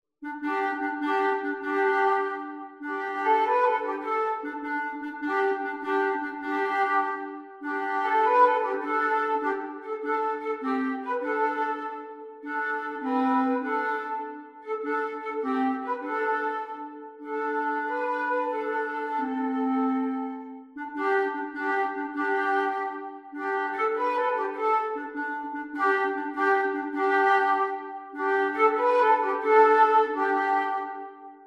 (Flute and Clarinet alone)